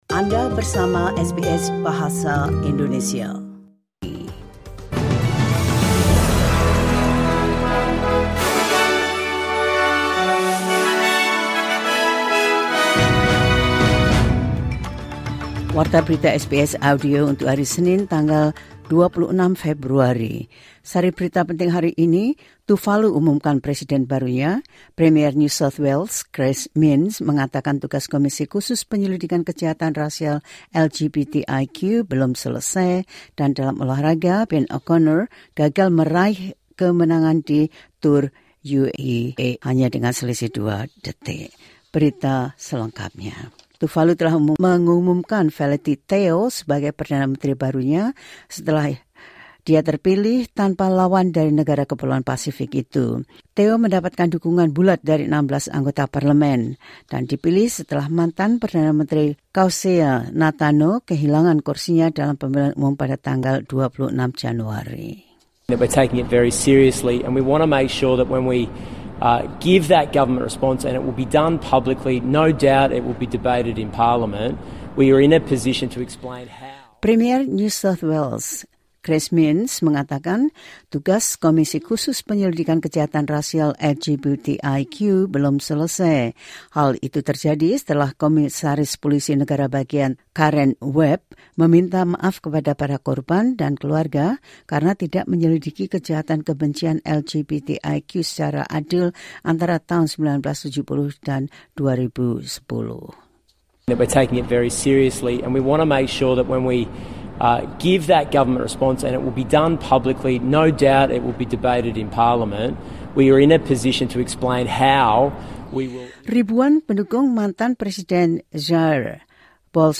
The latest news of SBS Audio Indonesian program – 26 Feb 2024